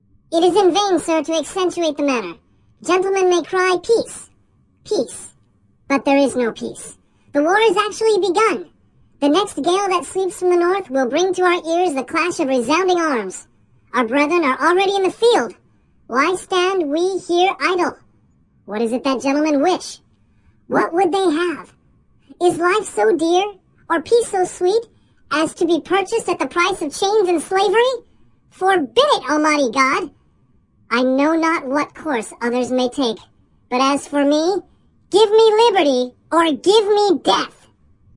描述：帕特里克亨利鼓舞人心的演讲的最后一段。录制并处理成由一只卡通花栗鼠说的。 录音是在Zoom H4n上进行的。使用MOTU Digital Performer中的Spectral Effects进行操纵